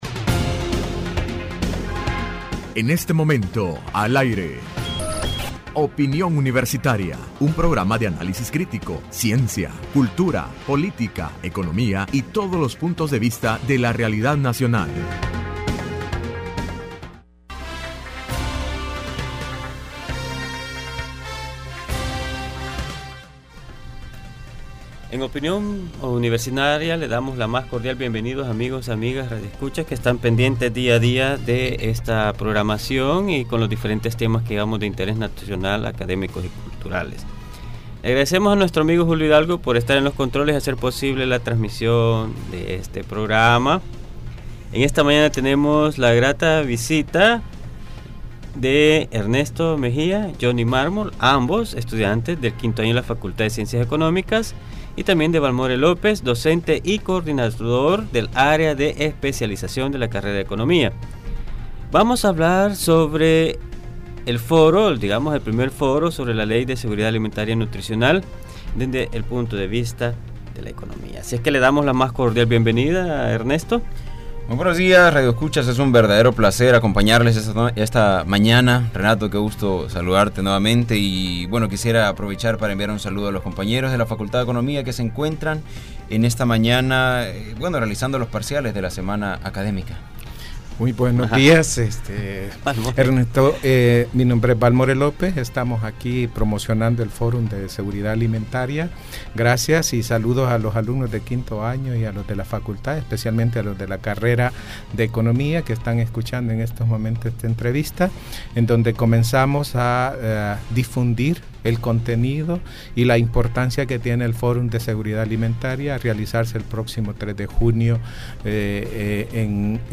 conversan sobre Primer foro económico sobre soberanía nacional y seguridad alimentaria y nutricional 2016, relacionado a las propuestas en el medio ambiente, ecología, política y los efectos económicos en la población desprotegida en el país.